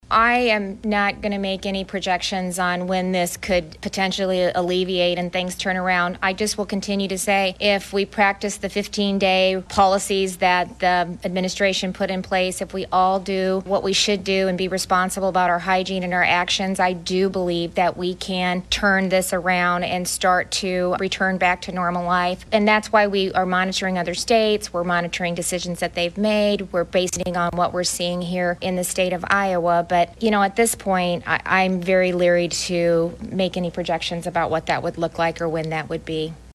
Gov. Kim Reynolds hosted another press conference this (Friday) afternoon and announced one additional case of COVID-19 had been identified in Iowa.
Friday-Presser-3.mp3